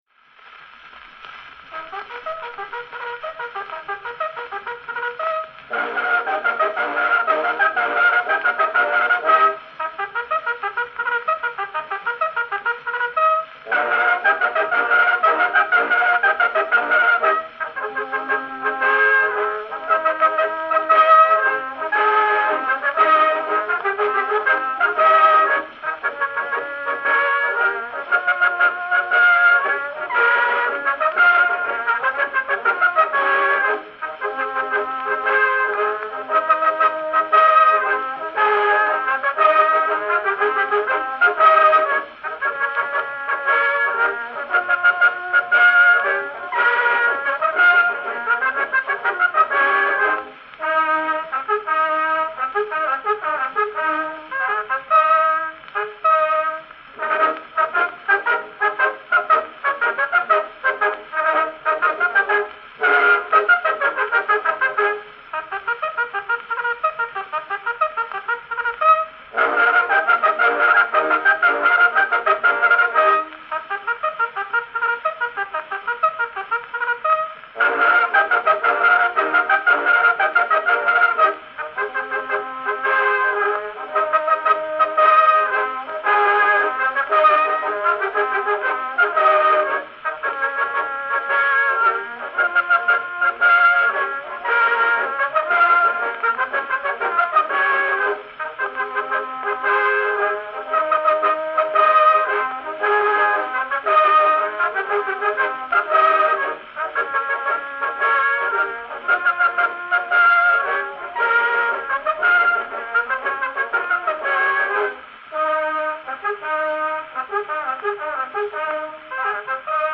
Grammophone Aufnahme 14698